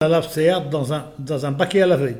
Enquête Arexcpo en Vendée
Locution ( parler, expression, langue,... )